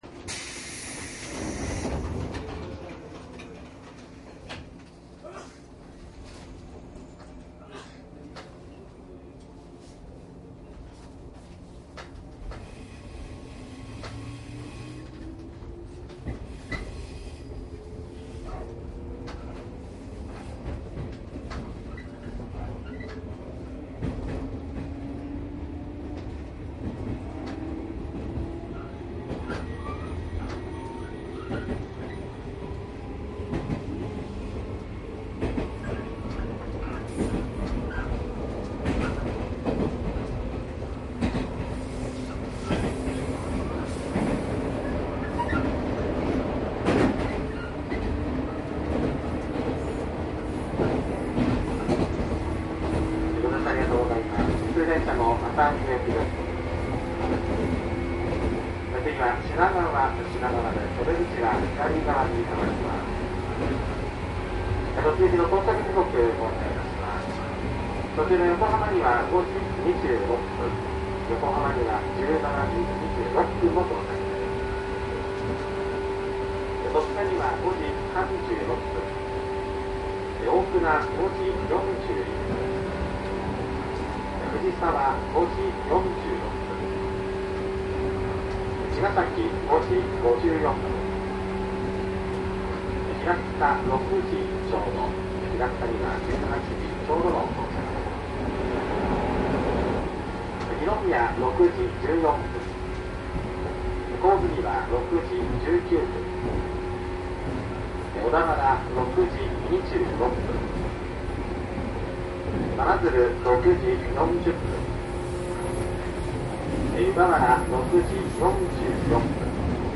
商品説明  ♪ＪＲ東海道線113系鉄道走行音 ＣＤ ♪
2000番台と1000番台による微妙な違いなどが楽しめます。
DATのSPモードで録音（マイクＥＣＭ959）で、これを編集ソフトでＣＤR化したものです。